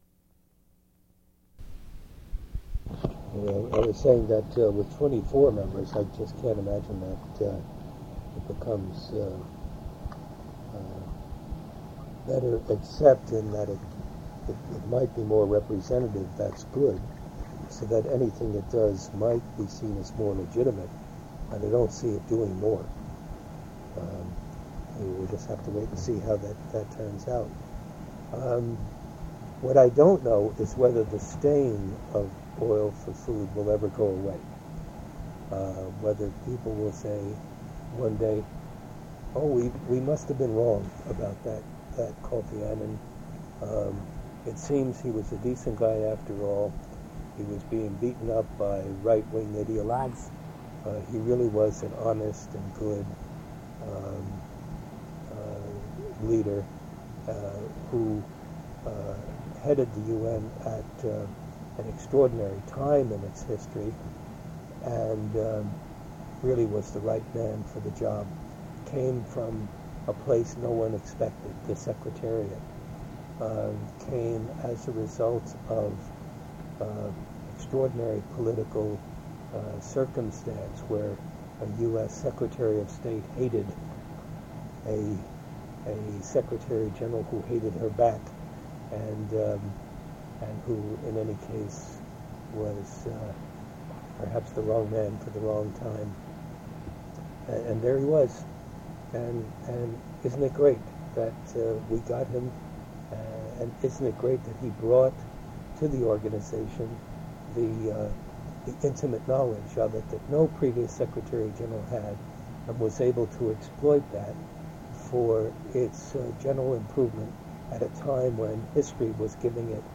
Interview with Frederic Eckhard /